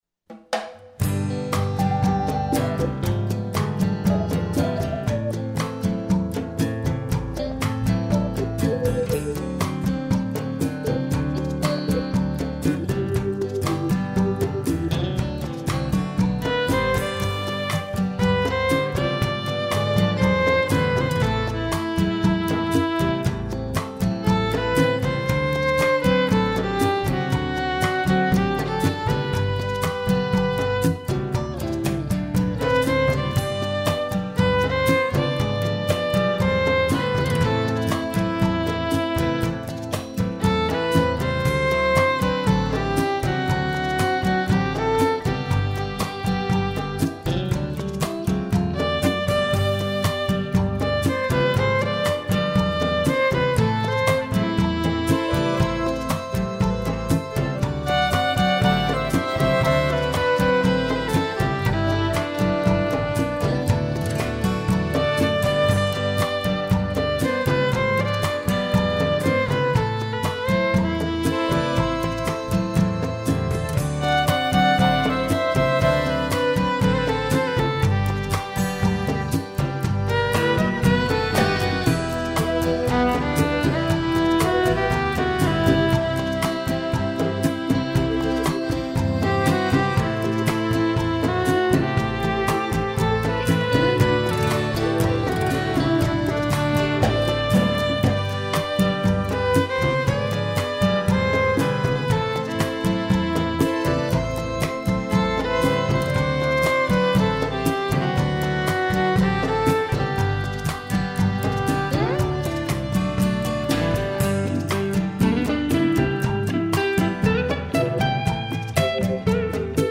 instrumental pieces